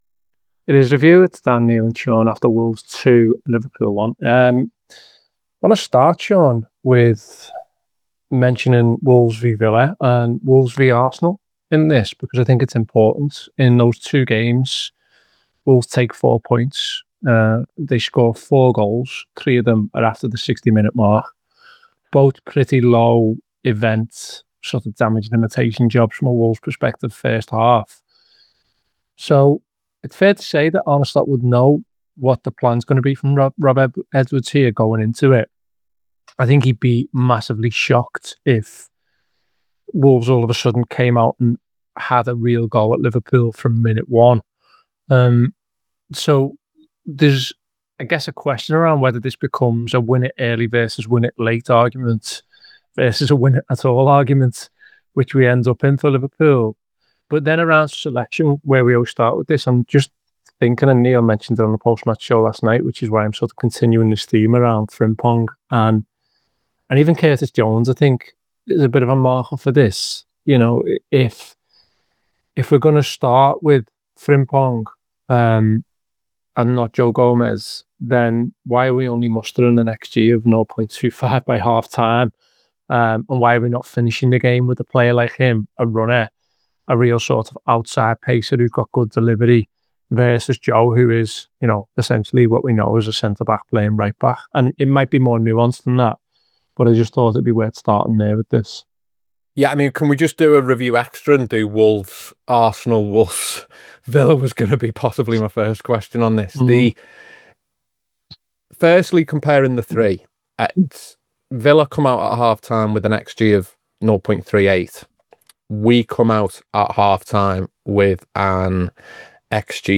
Below is a clip from the show – subscribe to The Anfield Wrap for more review chat…